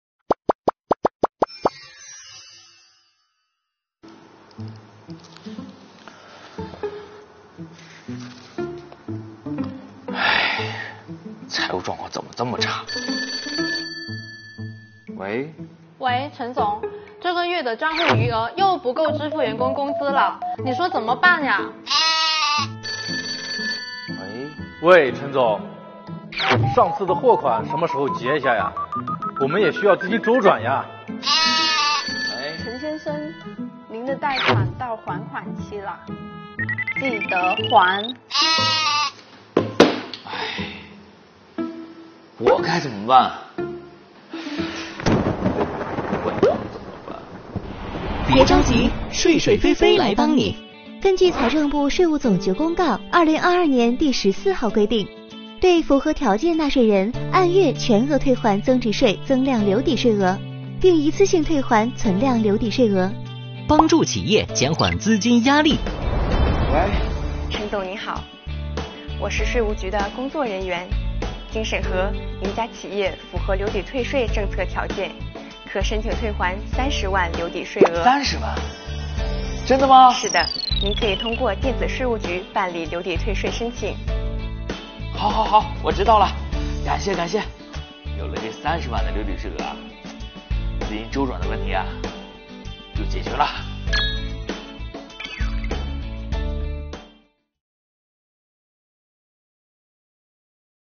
作品围绕主人公遇到的资金压力，讲述留抵退税政策及时为企业纾困解难，帮助企业渡难关。作品幽默诙谐的画面对比，适时的音乐特效，层层叠加的故事情节，生动诠释了“及时雨”的重要性。